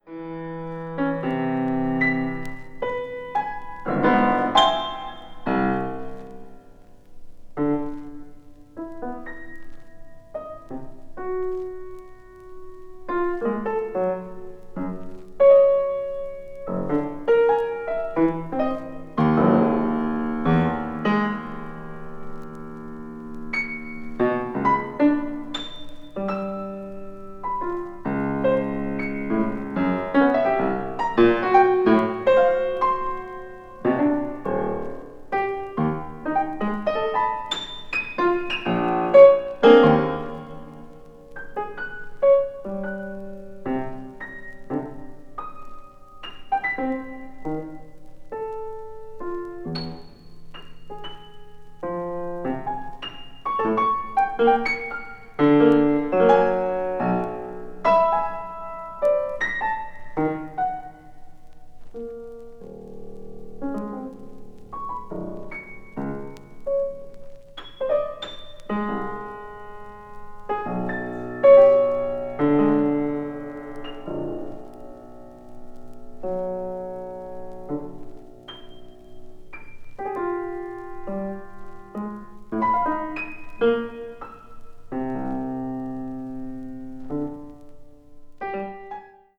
media : EX-/EX-,EX-/EX-(ごく薄いスリキズによるわずかなチリノイズが入る箇所あり)